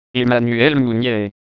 La pronuncia dei Filosofi - Diego Fusaro